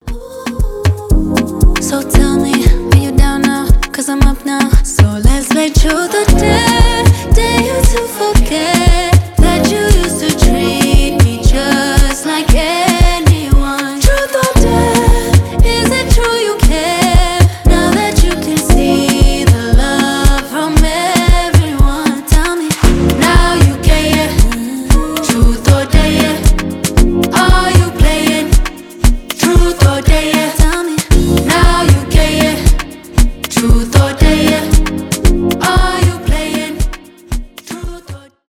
поп , зарубежные , танцевальные , rnb , afrobeat